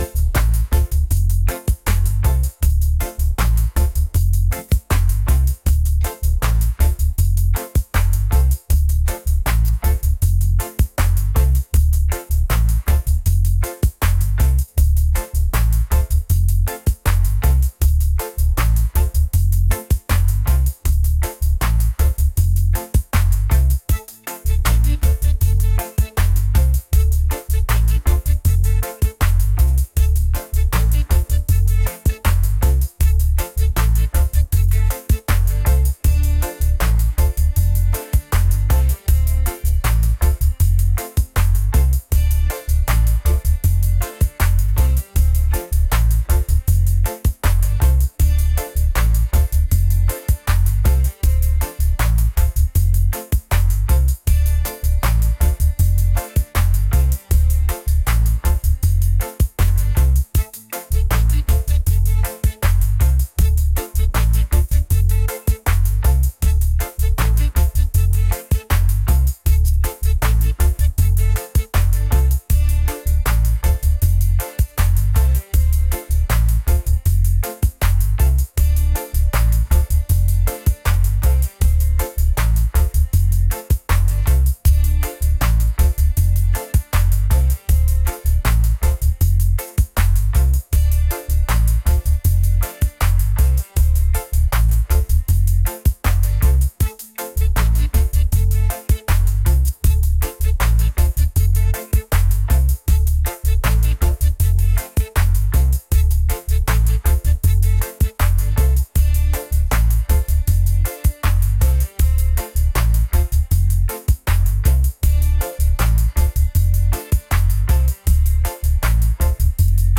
energetic | reggae